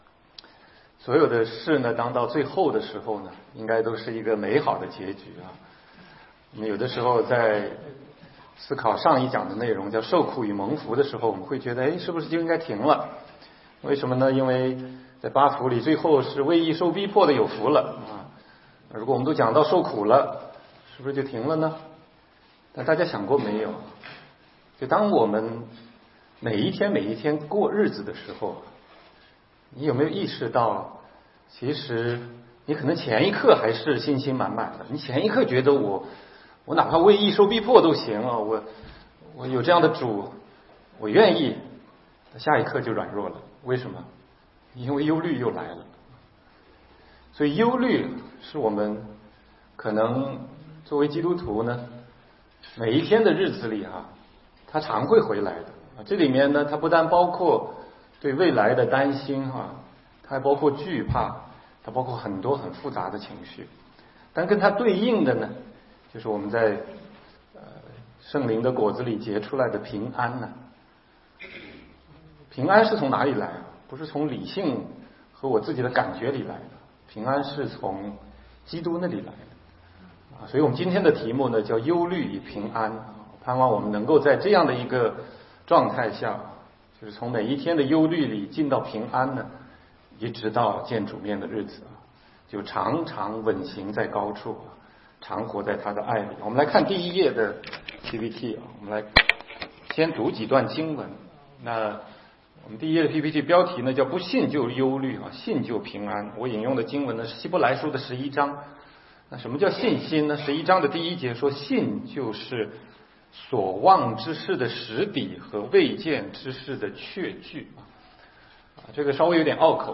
16街讲道录音 - 成圣的劝勉系列之七：忧虑与平安